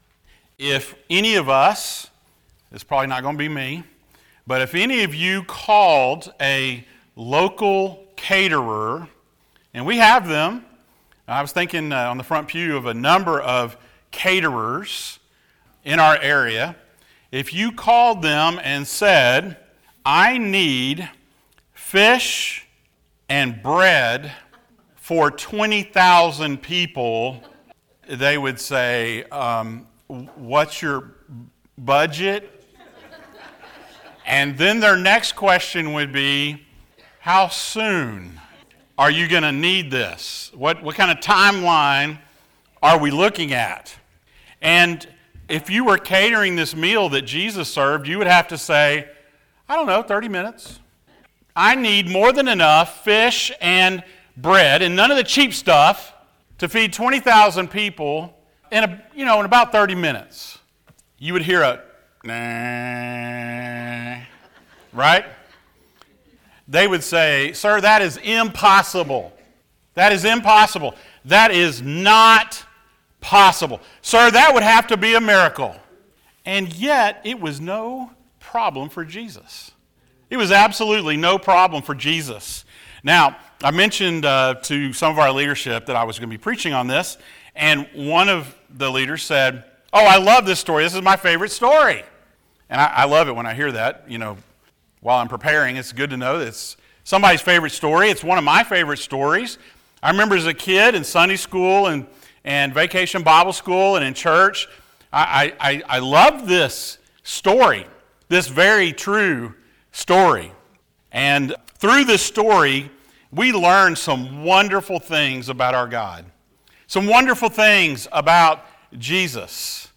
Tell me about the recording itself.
Passage: Mark 6:34-44 Service Type: Sunday Morning